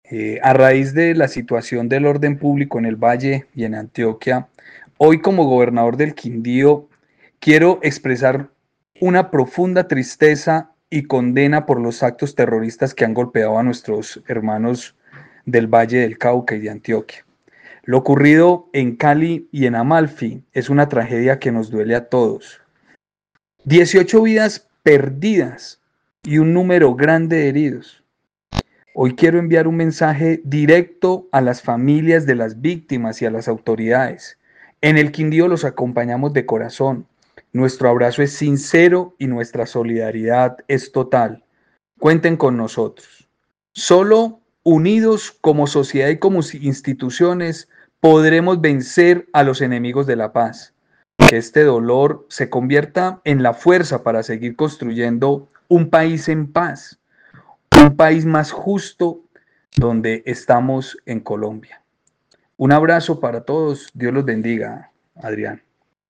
¡No más violencia, Colombia unida! mensaje del gobernador del Quindío por ola de violencia
Juan Miguel Galvis, gobernador del Quindío